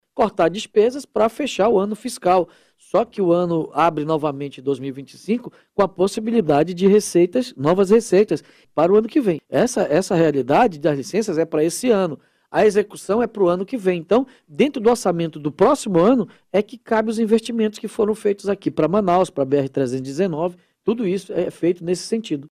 Em entrevista ao programa